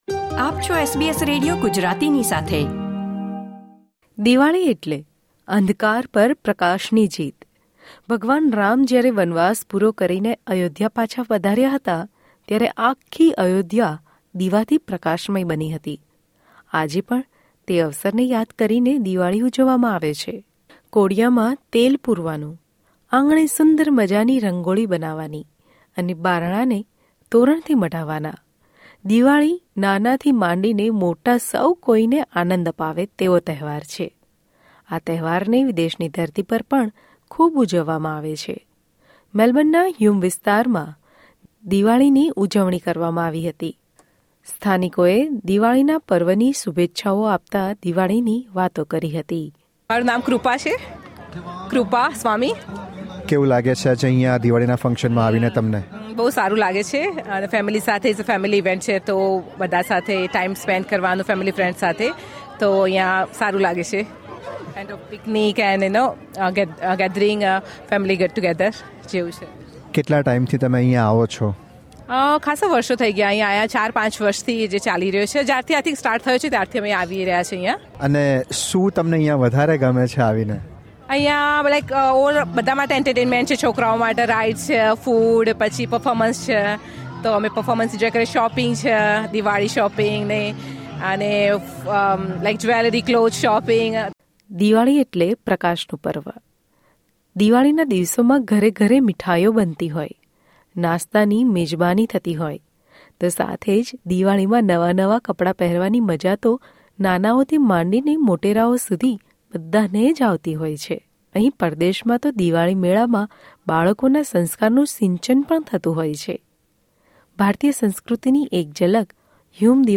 ઓસ્ટ્રેલિયાના મોટા શહેરોની સાથે રીજનલ વિસ્તારોમાં રહેલા ભારતીય મૂળના લોકો પણ સામૂહિક દિવાળીની ઉજવણી કરે છે. તાજેતરમાં મેલ્બર્નના હ્યુમ, ફેડરેશન સ્ક્વેર અને ન્યૂ સાઉથ વેલ્સના ક્વીનબિયાન ખાતે યોજાયેલા દિવાળી કાર્યક્રમની ઝલક.